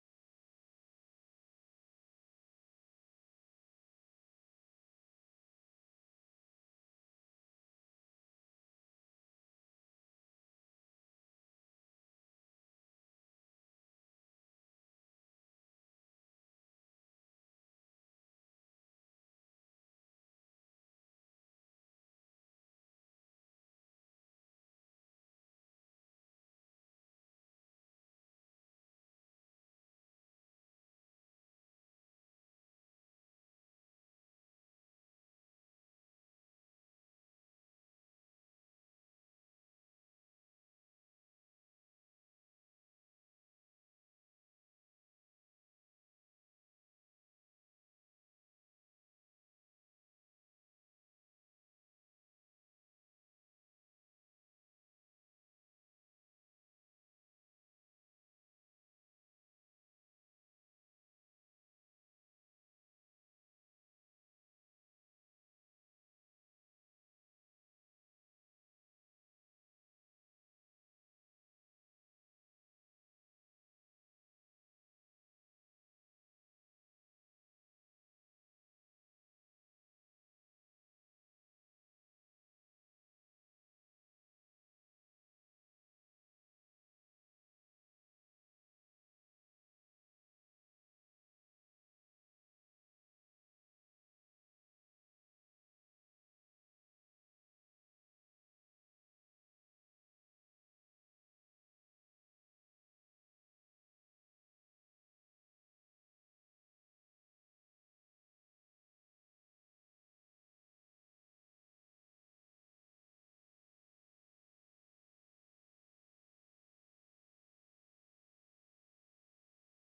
Track with sound cues for MUTE SCREAM score
CA_IDNO en -00075 Title en Track with sound cues for MUTE SCREAM score Description en This is a simple audio track that gives sound cues for the score Mute Scream which is the collective contribution from Spain for the tier 11 on time.
Time Location en Espacio Rueda / Güemes / Cantabria / Spain Interaction Time en More than 10 minutes Collective en Spain Type of Contribution en Collective Media TRACK WITH SOUND CUES FOR MUTE SCREAM.mp3